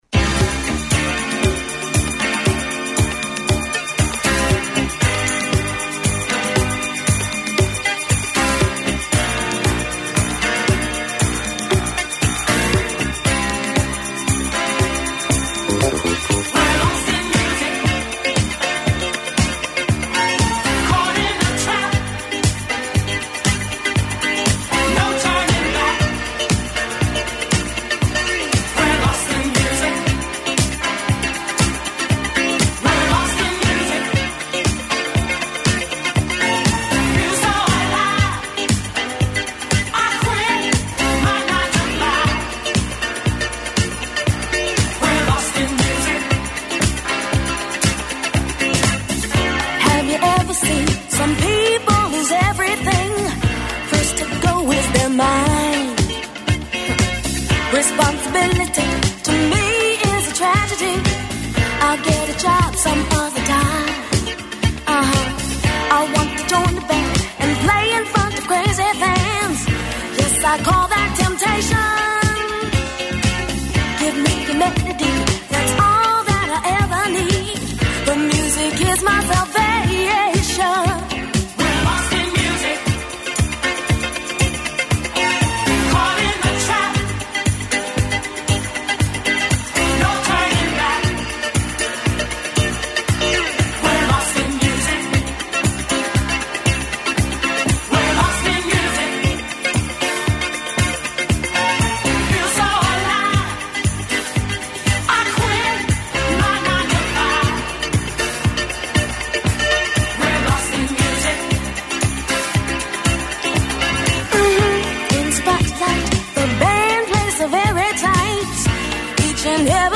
Ακούστε το σχόλιο του Νίκου Χατζηνικολάου στον ραδιοφωνικό σταθμό RealFm 97,8, την Δευτέρα 31 Μαρτίου 2025.